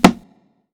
TC PERC 14.wav